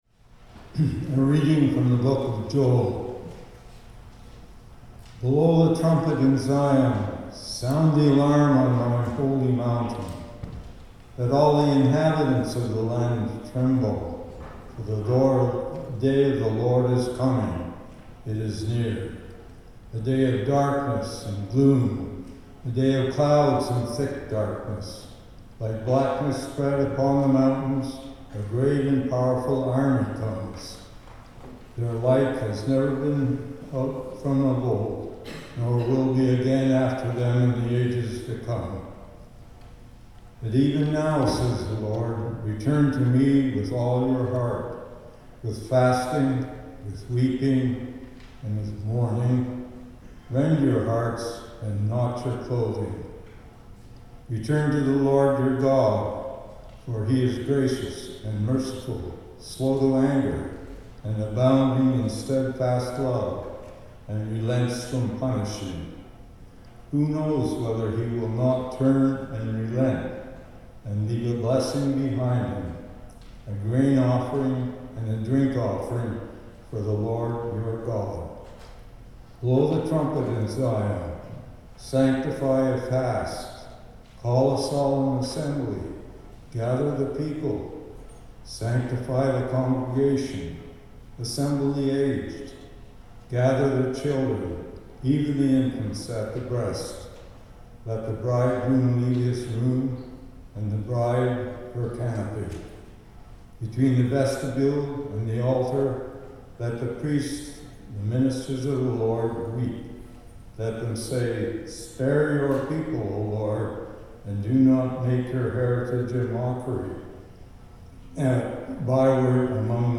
The recording begins with the first reading due to microphone issues.
The Lord’s Prayer (sung)